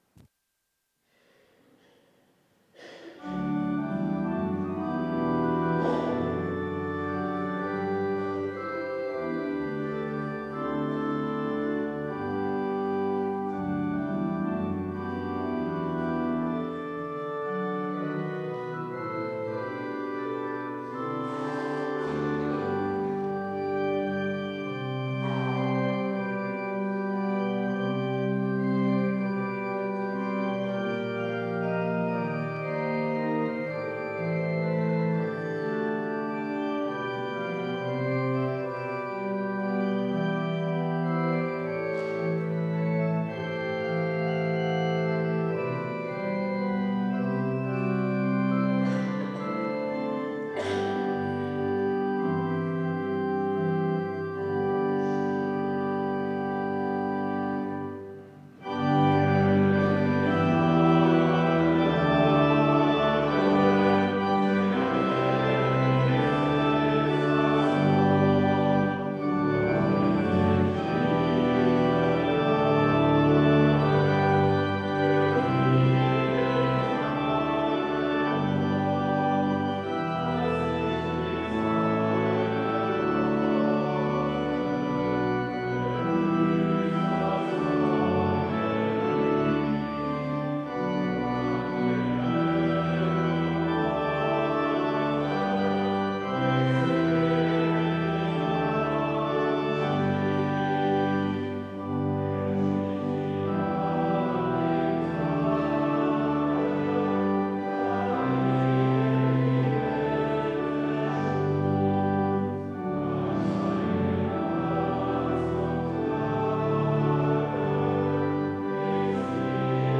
Er weckt mich alle Morgen... (LG 413,1-3) Ev.-Luth. St. Johannesgemeinde Zwickau-Planitz
Audiomitschnitt unseres Gottesdienstes vom 19. Sonntag nach Trinitatis 2025.